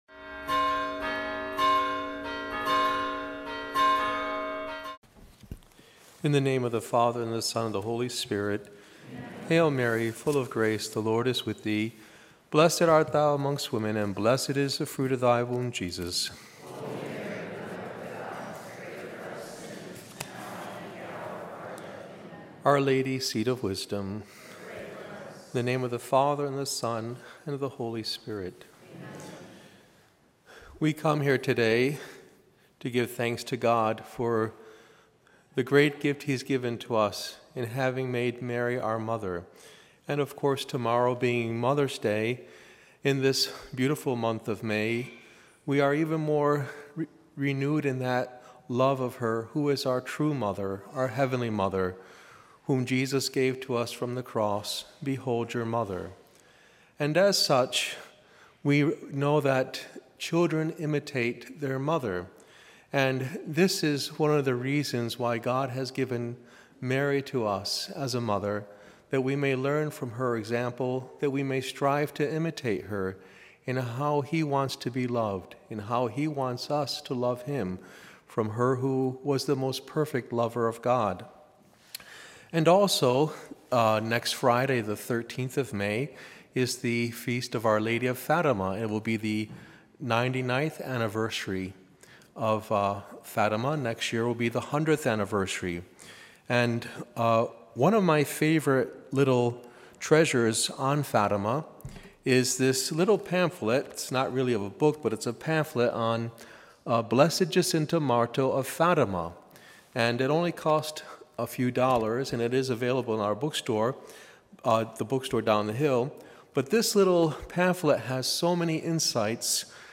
Homily
gives the homily on our Spring 2016 Marian Day